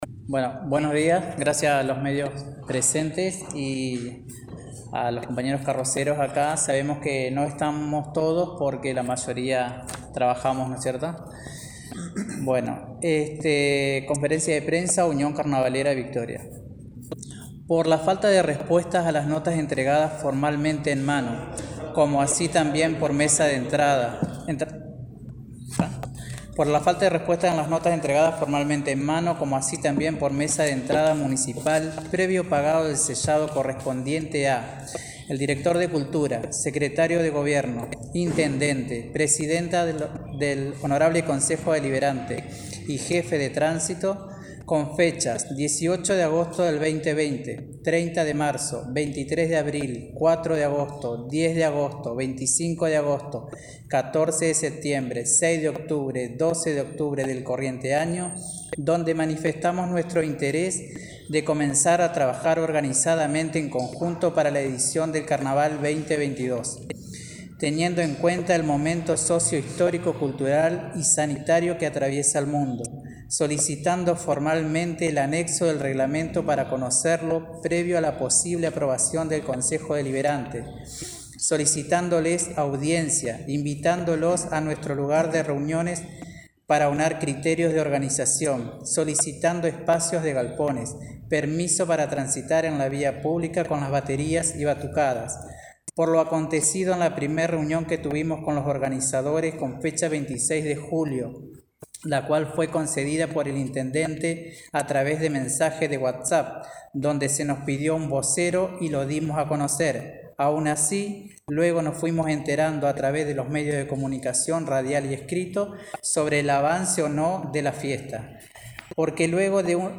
Conferencia de Prensa de Unión Carnavalera
conferencia.mp3